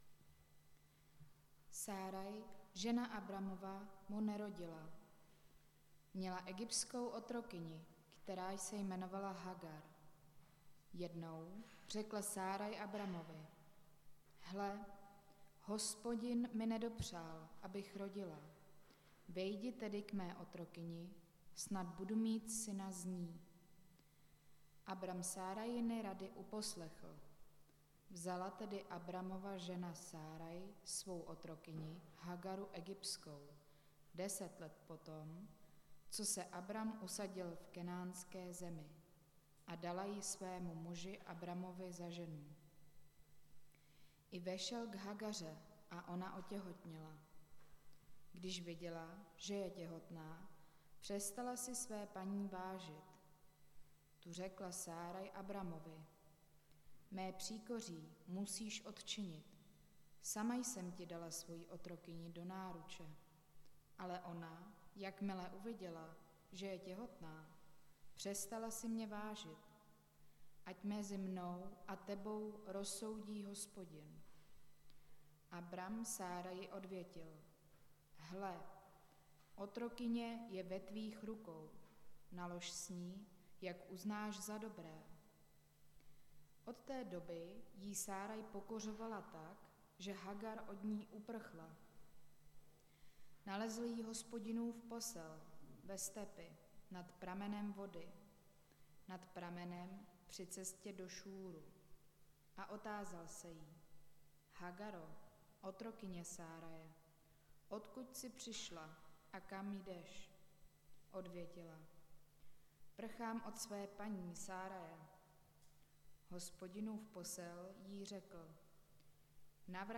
Nedělní kázání – 16.10.2022 Sáraj a Hagar